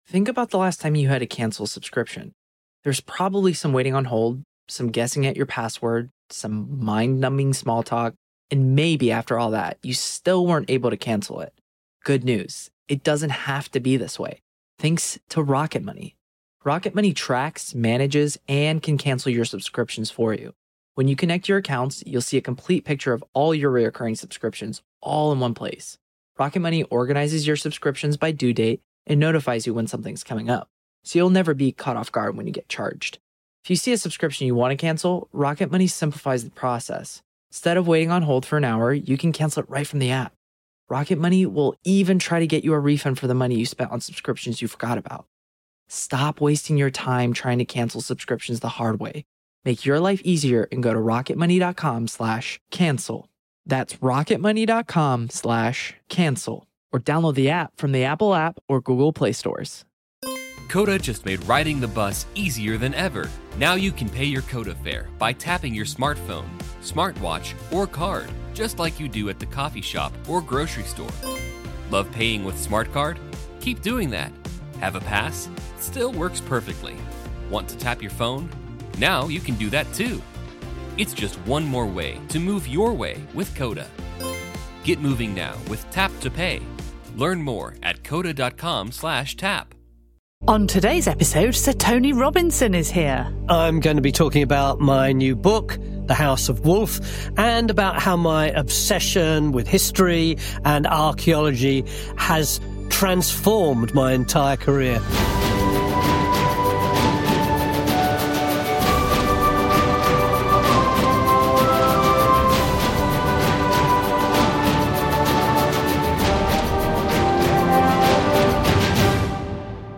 In this special edition of the Time Team podcast, archaeologist Helen Geake speaks to the man who's been at the heart of Time Team since the very beginning, Sir Tony Robinson!